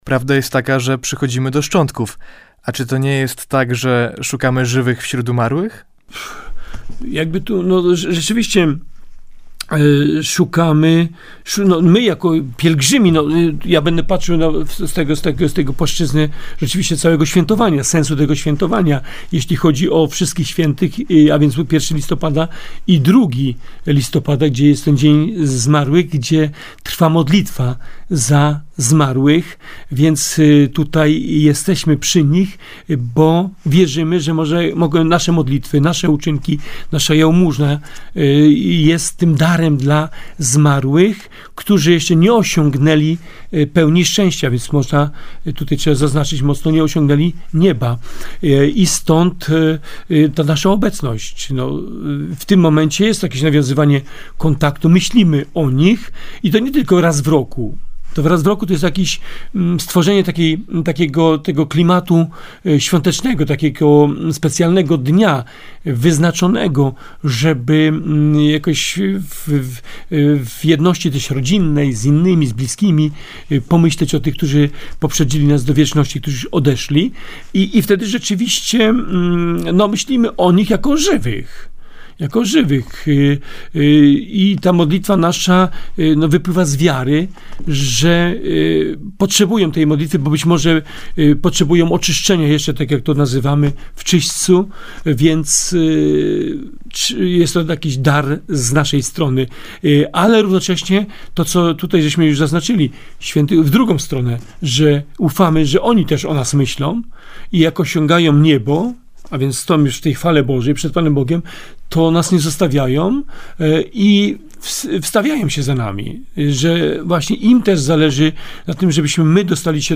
Bp Krzysztof Włodarczyk był gościem "Wieczornych Spotkań" w Polskim Radiu Koszalin.
Nagrania dzięki uprzejmości Polskiego Radia Koszalin.